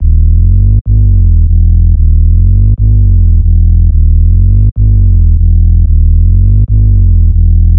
• tech house bass samples - Em - 123.wav
tech_house_bass_samples_-_Em_-_123_N4i.wav